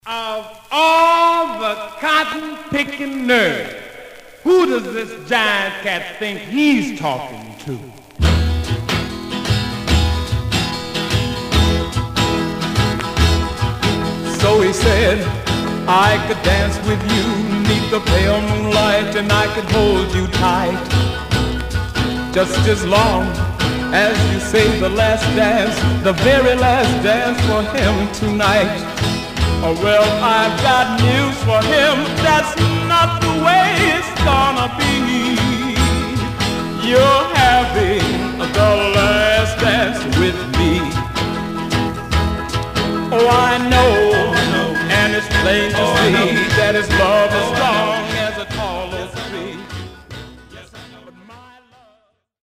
Condition Some surface noise/wear Stereo/mono Mono